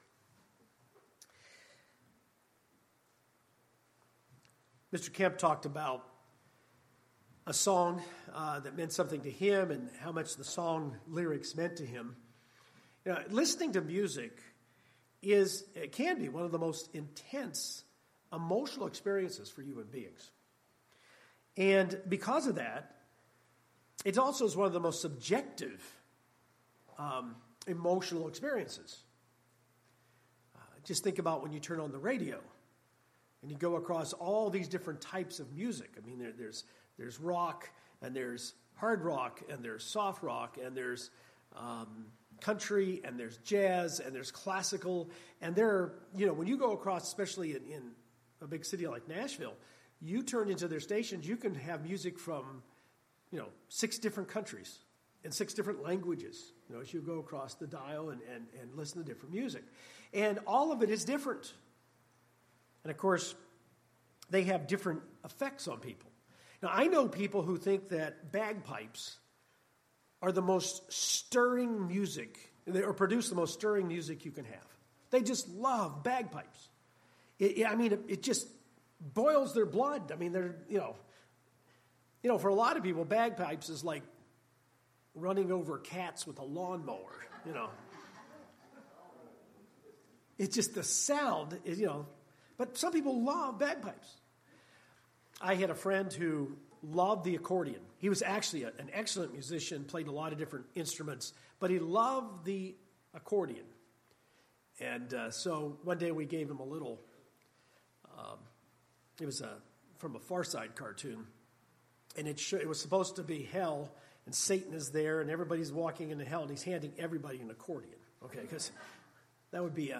Music has very powerful effects on us and can be used to praise and worship in our services. This sermon covers the proper use and preparation for using music in our worship of God.